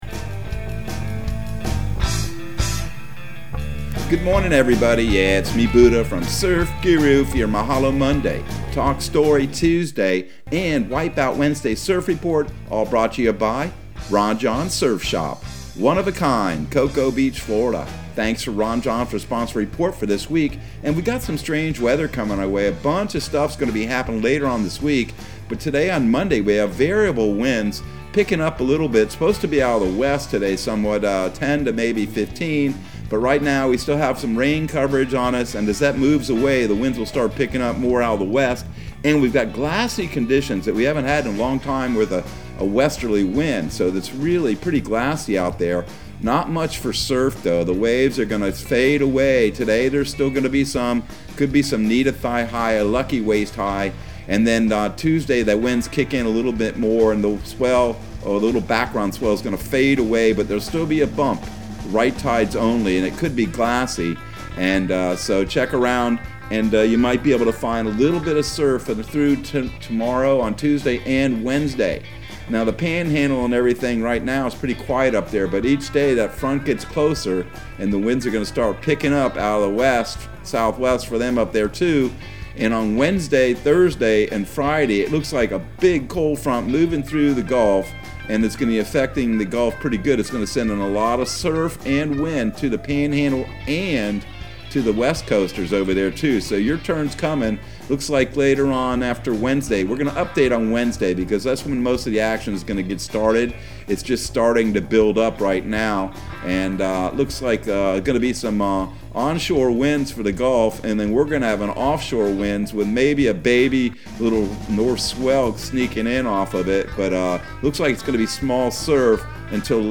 Surf Guru Surf Report and Forecast 10/25/2021 Audio surf report and surf forecast on October 25 for Central Florida and the Southeast.